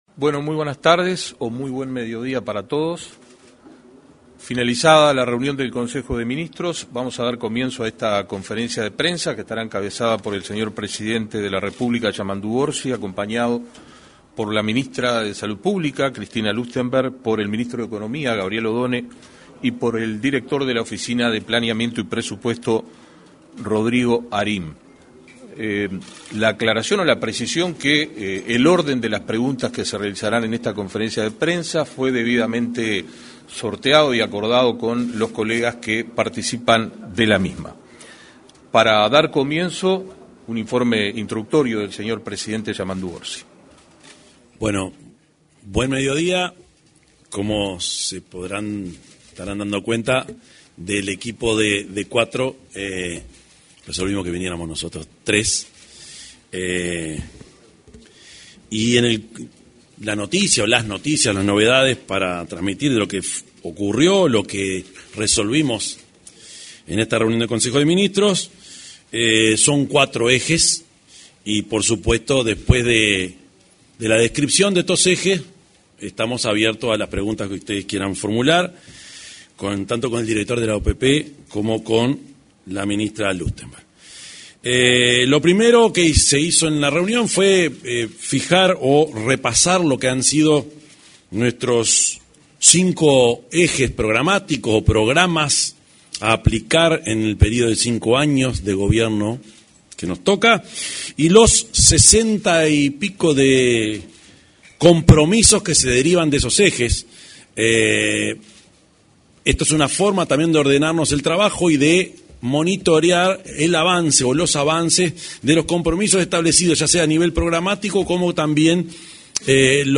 Conferencia de prensa - Consejo de Ministros
Conferencia de prensa - Consejo de Ministros 25/03/2025 Compartir Facebook X Copiar enlace WhatsApp LinkedIn Este martes 25 se realizó una conferencia de prensa, luego de finalizado el Consejo de Ministros. En la oportunidad se expresó el presidente de la República, profesor Yamandú Orsi; la ministra de Salud Pública, Cristina Lustemberg, y el director de la Oficina de Planeamiento y Presupuesto, Rodrigo Arim.